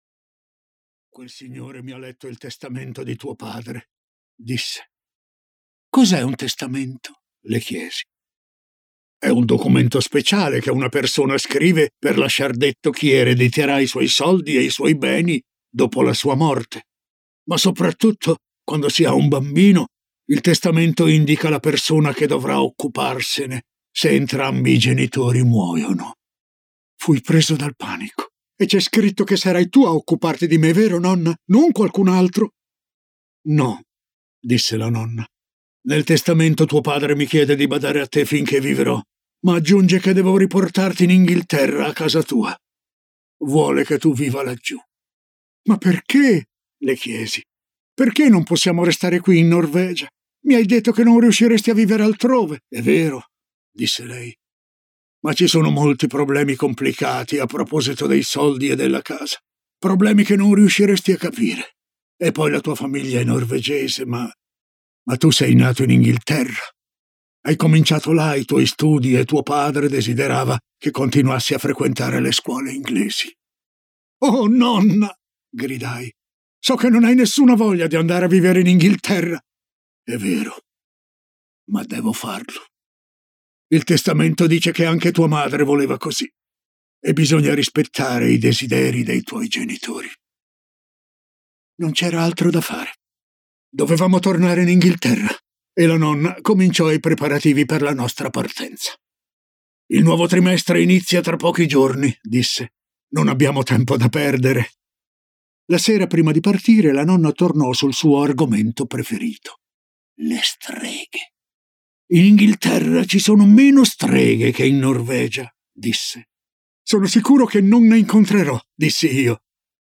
"Le streghe" di Roald Dahl - Audiolibro digitale - AUDIOLIBRI LIQUIDI - Il Libraio